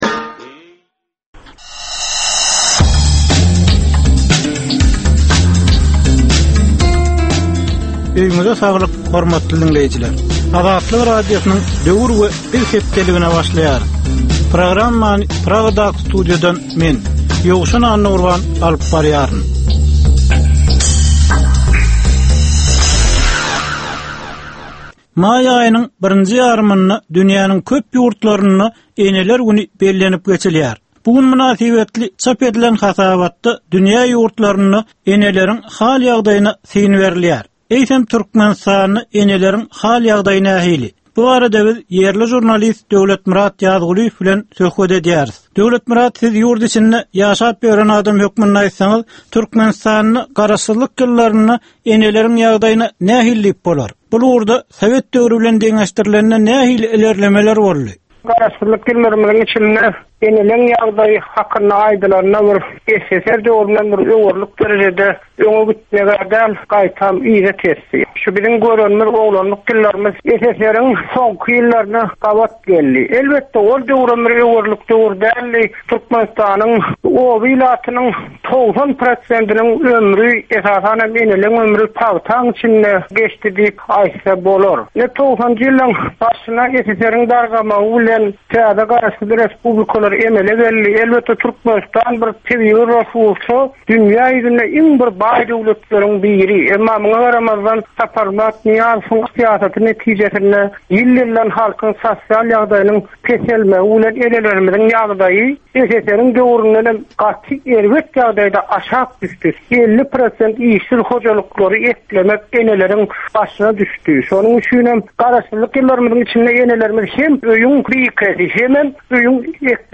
Türkmen jemgyýetindäki döwrüň meseleleri. Döwrüň anyk bir meselesi barada ýörite syn-gepleşik. Bu gepleşikde diňleýjiler, synçylar we bilermenler döwrüň anyk bir meselesi barada pikir öwürýärler, öz garaýyşlaryny we tekliplerini orta atýarlar.